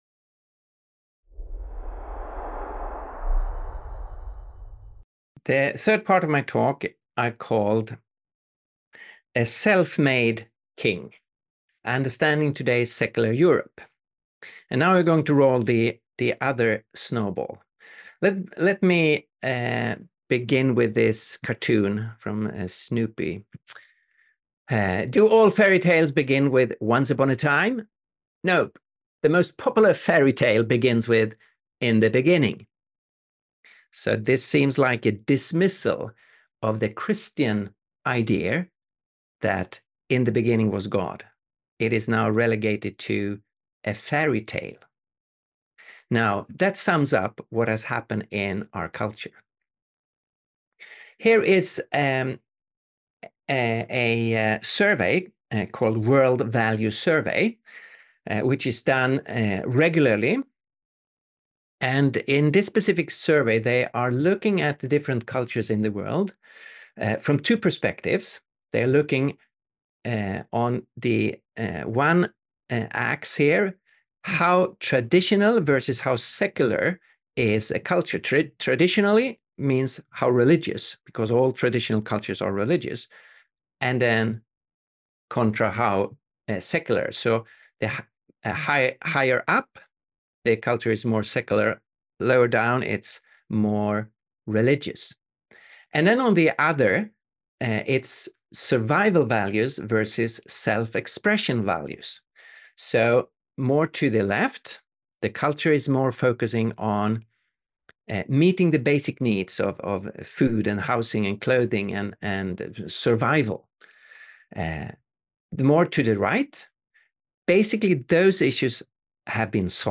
Master Class